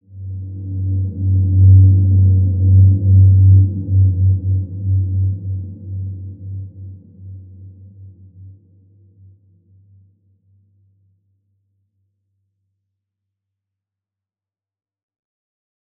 Large-Space-G2-mf.wav